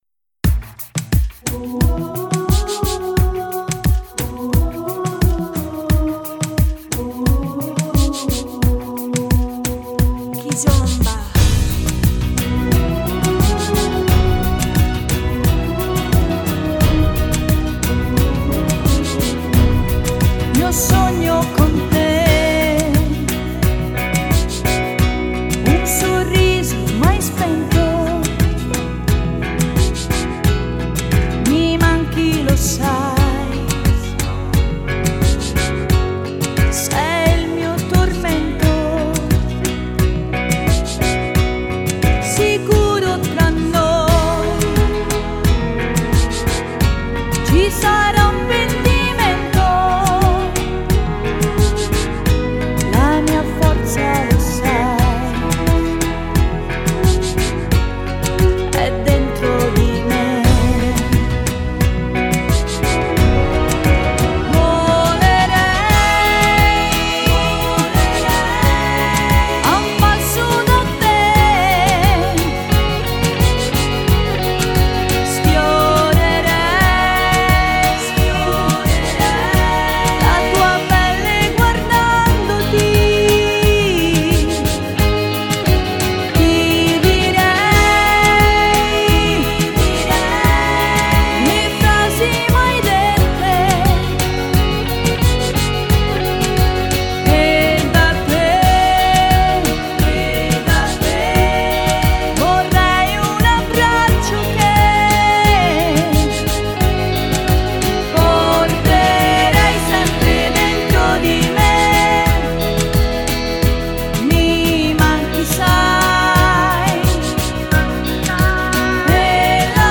(Kizomba)